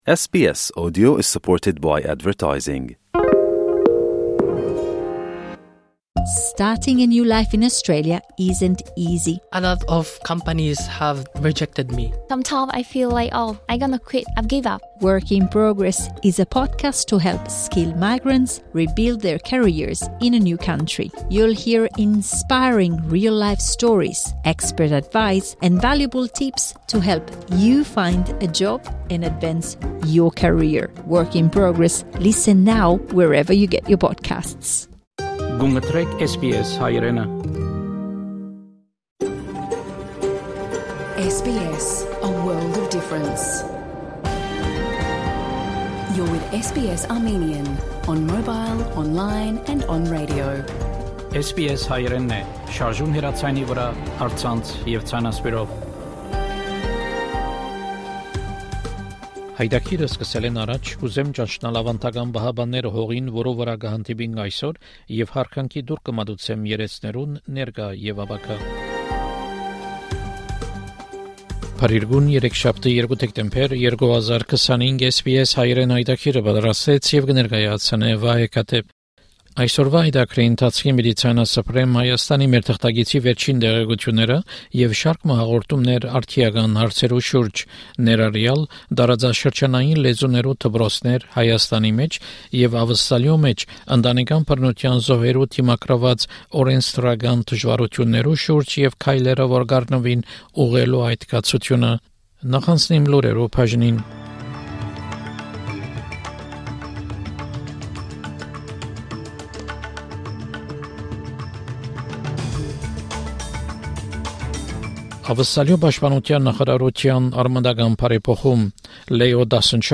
SBS Armenian news bulletin from 2 December 2025 program.